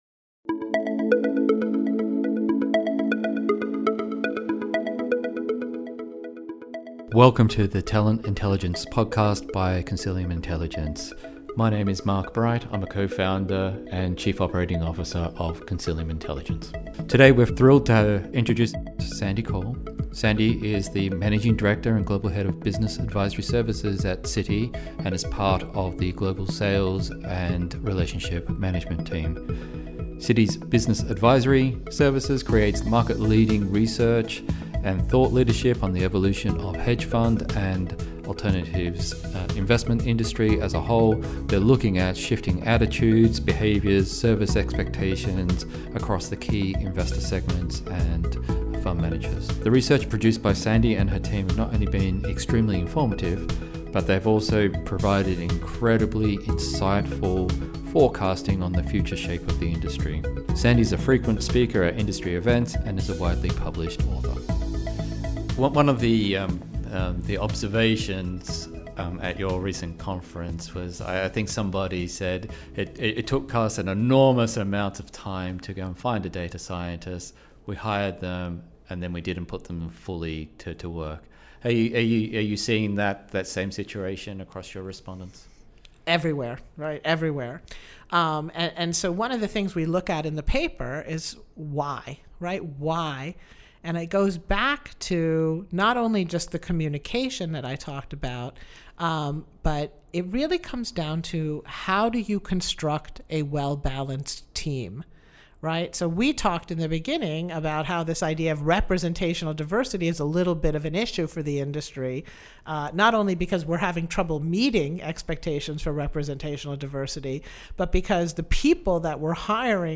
The newly produced podcast series was created with a focus on data science, cultural alignment, and technology in the competitive fields of alternatives, investment banking and tech verticals. Listeners get the opportunity to tune-in as the hosts debate the latest techniques in identifying, connecting and retaining the world’s top minds and most sought-after talent.